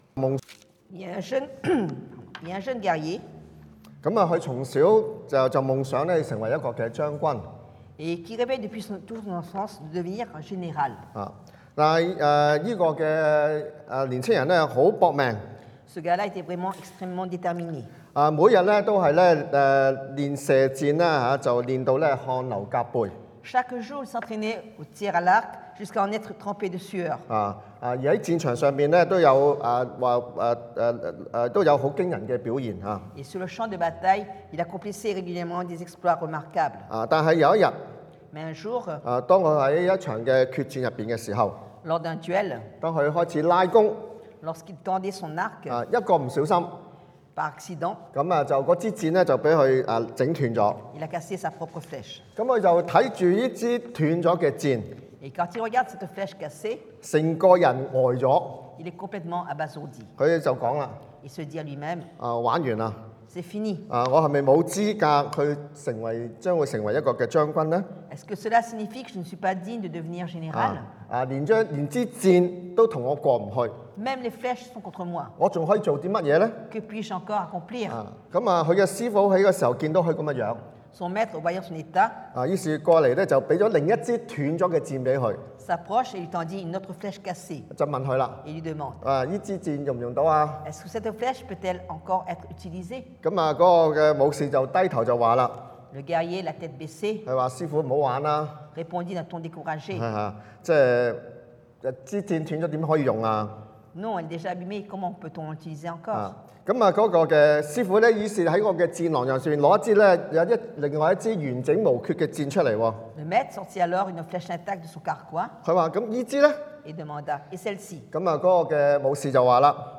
Passage: Genèse 創世記 6:11-22 Type De Service: Predication du dimanche « Du mur au pont